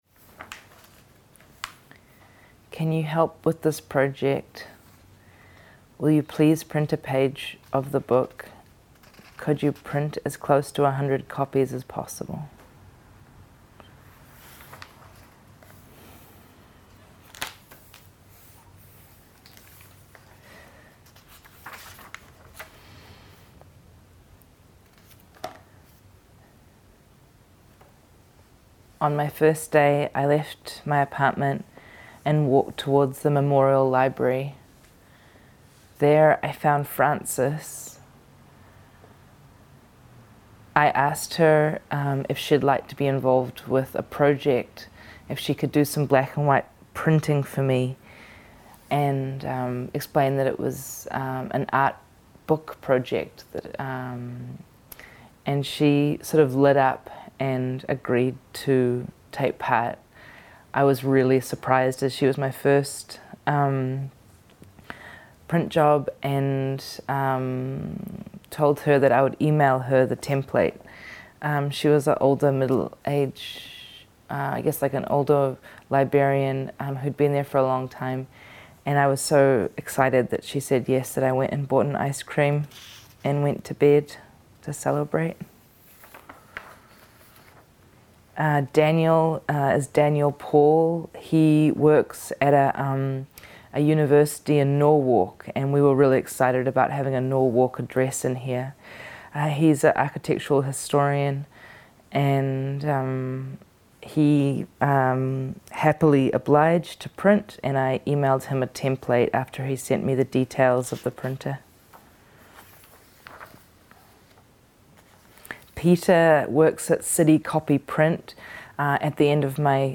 THE STORY IS READ BY THE ARTIST EXCEPT … PAGES ARE BLANK.